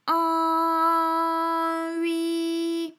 ALYS-DB-001-FRA - First, previously private, UTAU French vocal library of ALYS
an_an_ui.wav